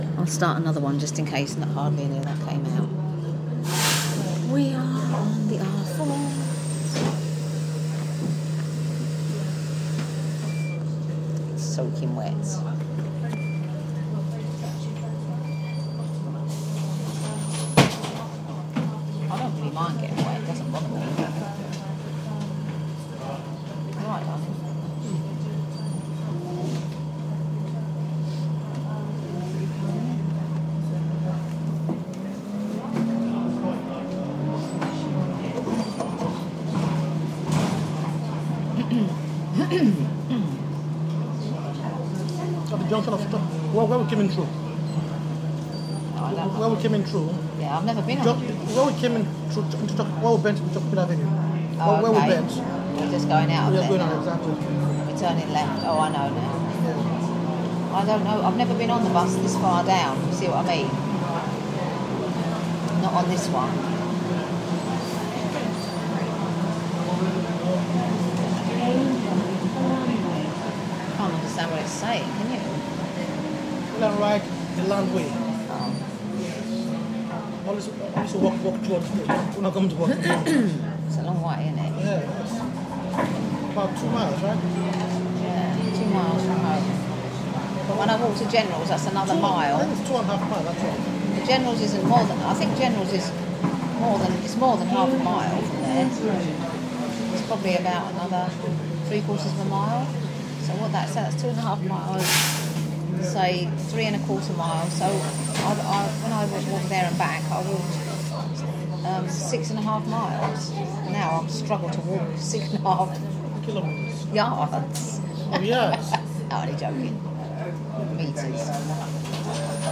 coming home on the bus